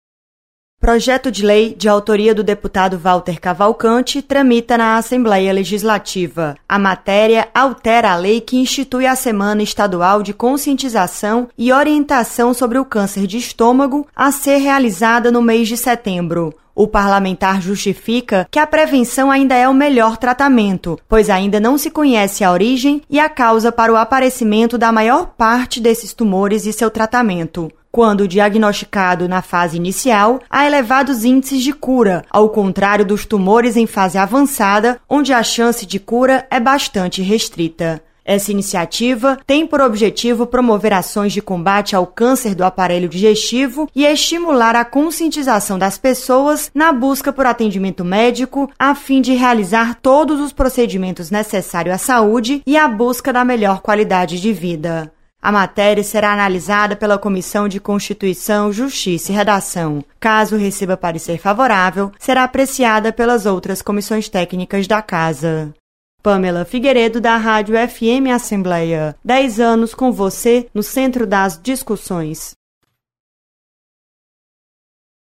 Você está aqui: Início Comunicação Rádio FM Assembleia Notícias Projeto